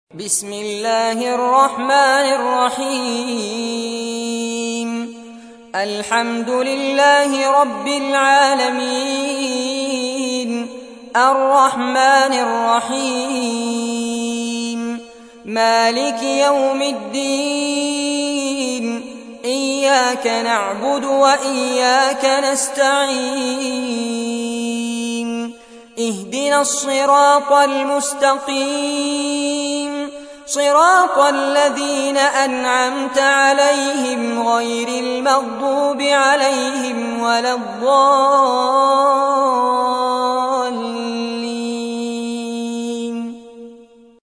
تحميل : 1. سورة الفاتحة / القارئ فارس عباد / القرآن الكريم / موقع يا حسين